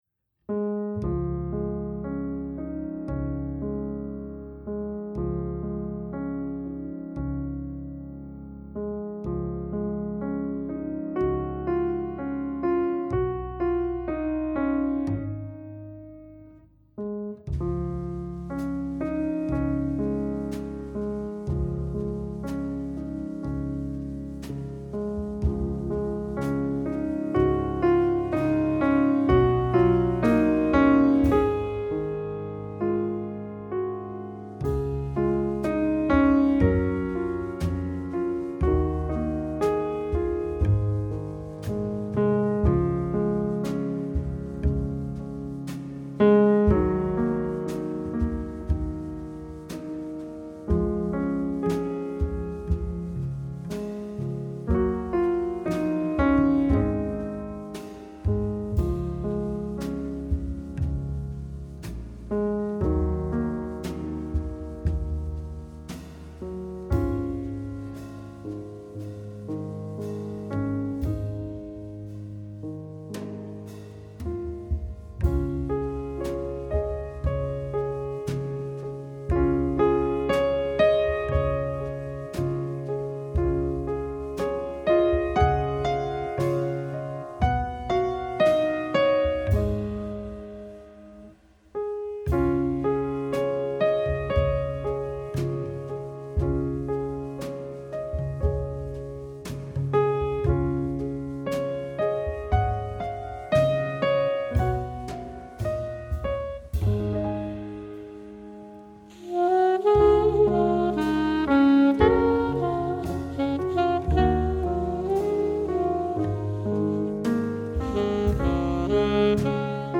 to help you relax at bedtime